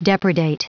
Prononciation du mot depredate en anglais (fichier audio)
Prononciation du mot : depredate